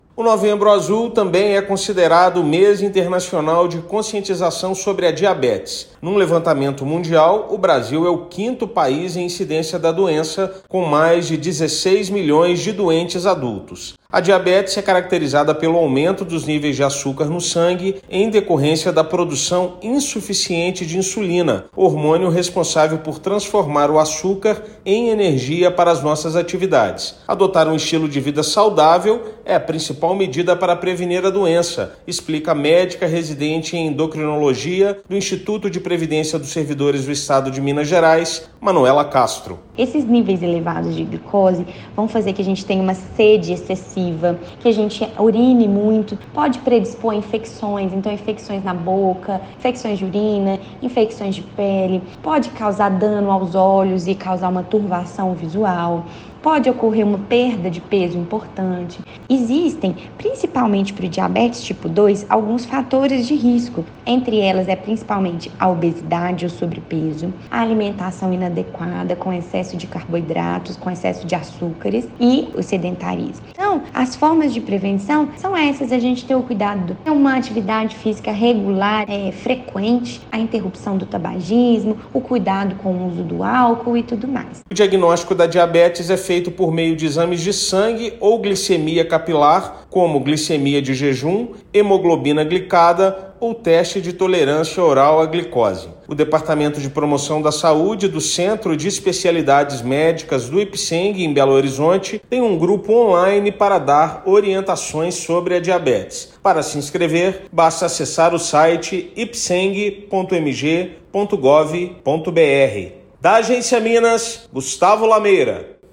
Adotar um estilo de vida saudável é a principal medida para prevenir a doença. Ouça matéria de rádio.